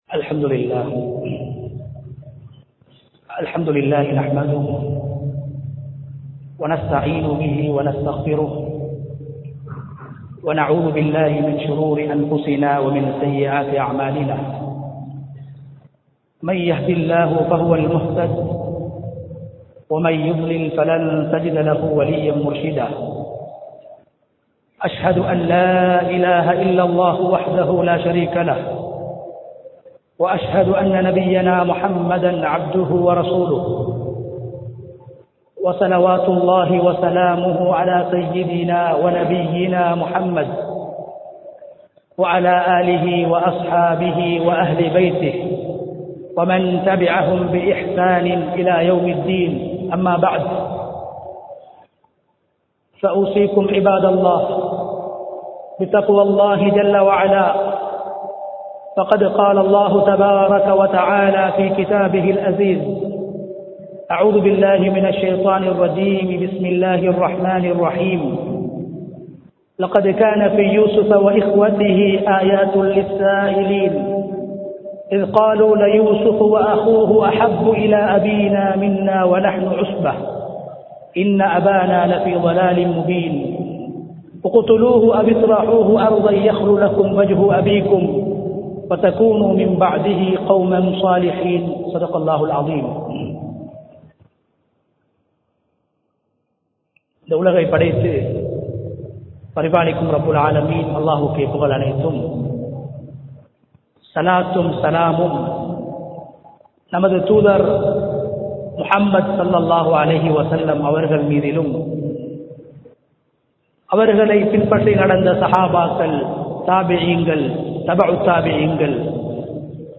குழந்தைகளுடன் நீதமாக நடப்போம் | Audio Bayans | All Ceylon Muslim Youth Community | Addalaichenai
Hurimaluva Jumua Masjidh